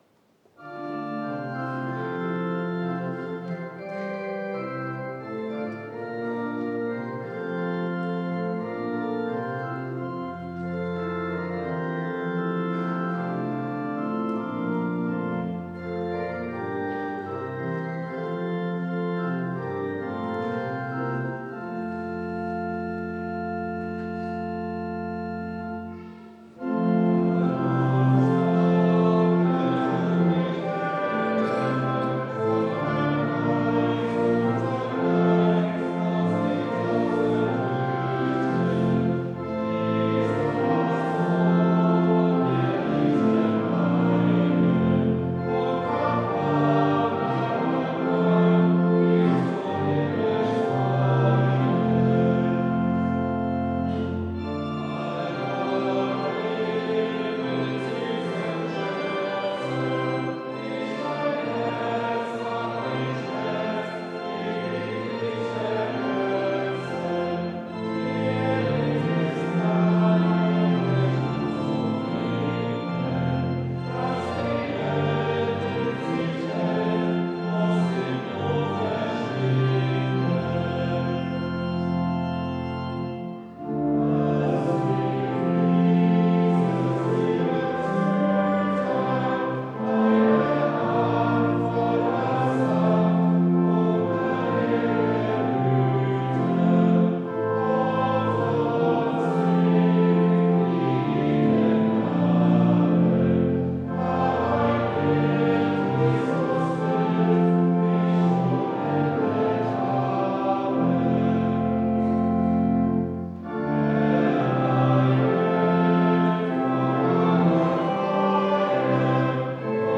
Audiomitschnitt unseres Gottesdienstes vom 2. Sonntag nach Ostern 2025.